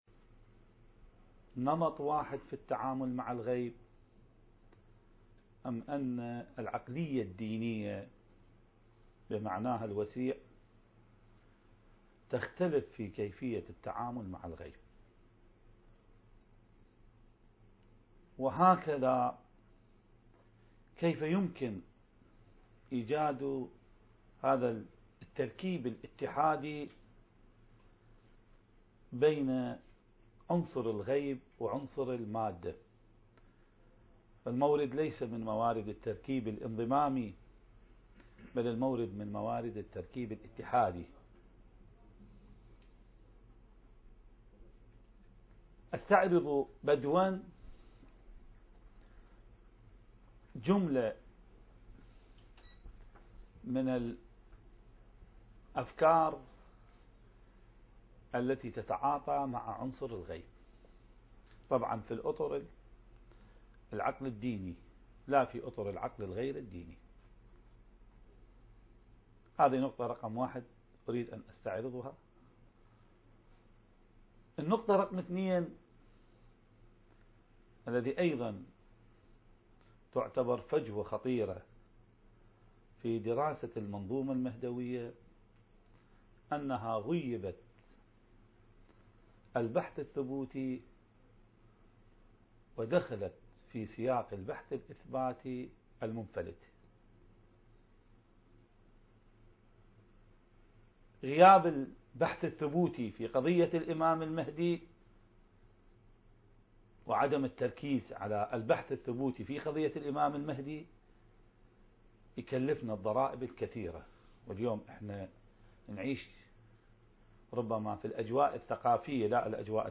الندوة المهدوية بمناسبة الشهادة الفاطمية الثانية والتي عقدت تحت شعار (ولي في ابنة رسول الله اسوة حسنة) المكان: قاعة مركز الدراسات التخصصية في الإمام المهدي (عجّل الله فرجه) في النجف الاشرف التاريخ: 2016